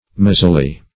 mazily - definition of mazily - synonyms, pronunciation, spelling from Free Dictionary
mazily - definition of mazily - synonyms, pronunciation, spelling from Free Dictionary Search Result for " mazily" : The Collaborative International Dictionary of English v.0.48: Mazily \Ma"zi*ly\, adv.